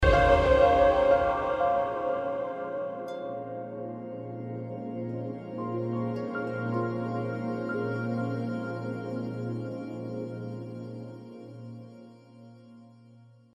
هشدار پیامک